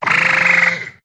Cri de Batracné dans Pokémon HOME.